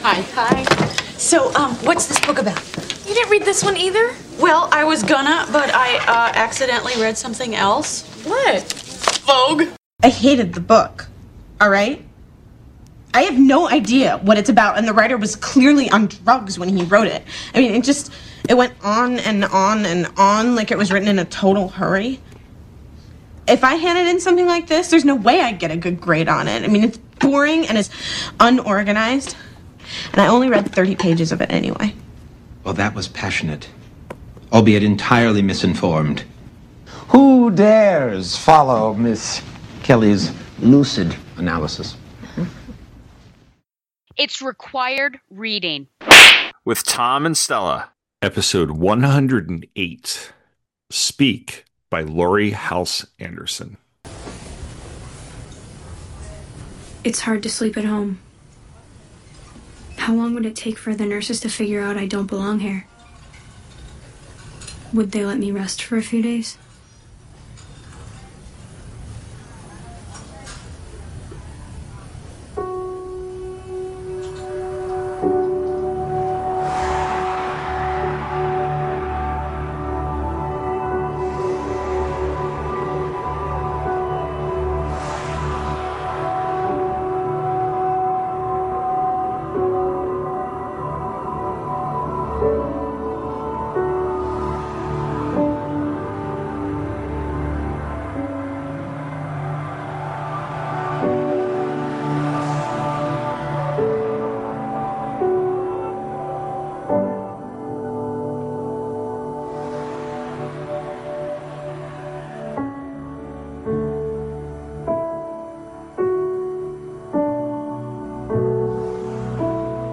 is two teachers talking about literature.